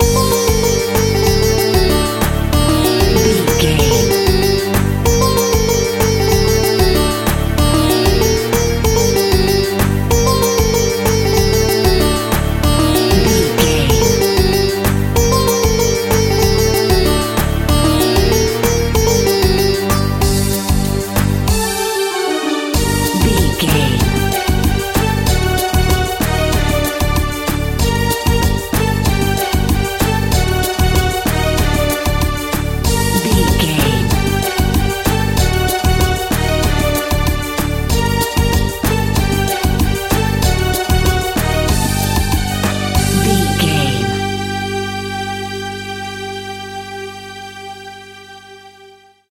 Aeolian/Minor
percussion
congas
kora
djembe
kalimba
talking drum